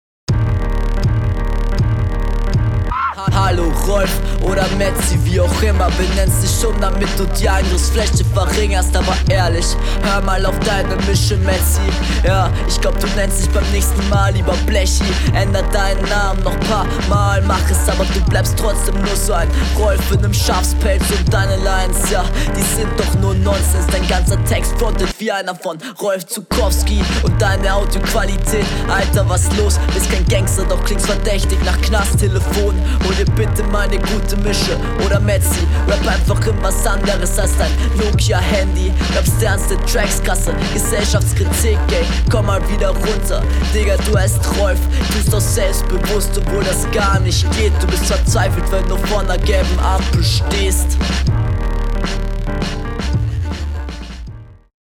➨ Takt wird eigentlich durchwegs getroffen.
Sehr interessanter Beatpick und auch sehr eigene Art zu rappen.